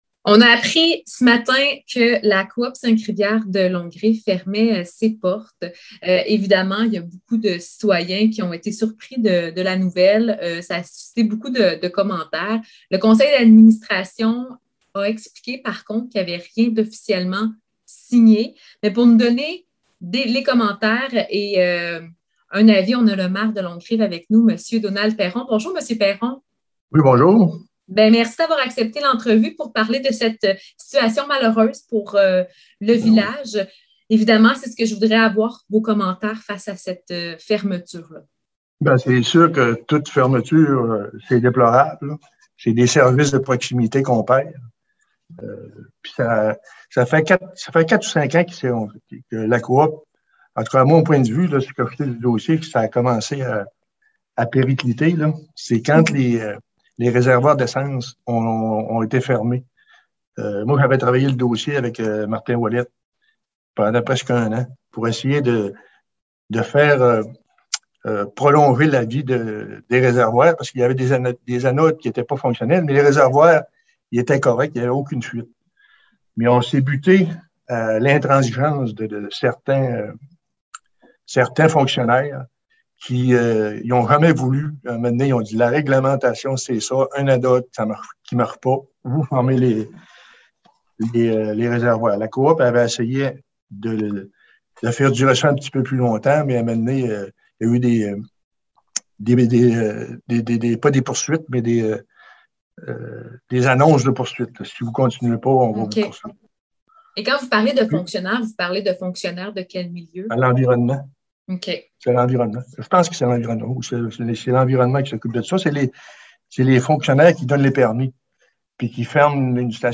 Fermeture de la Coop des 5 rivières de Longue-Rive : le maire réagit